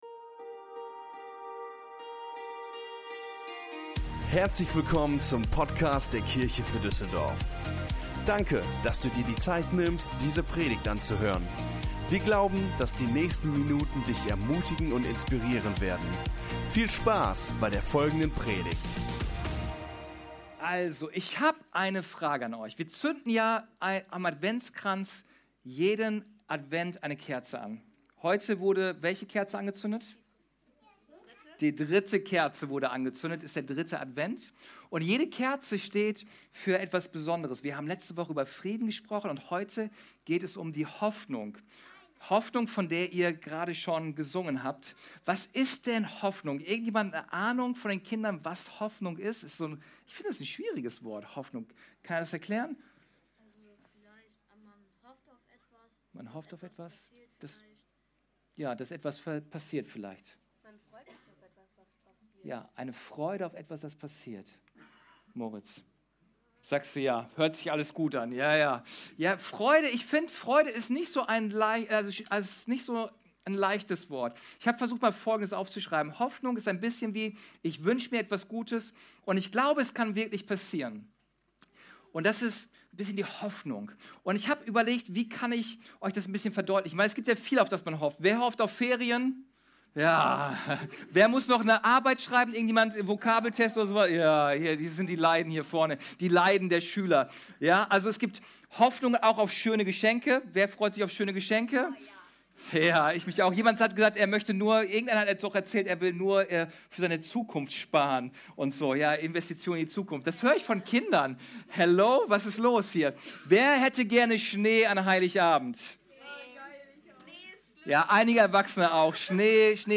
6_Familien_Gottesdienst_mixdown.mp3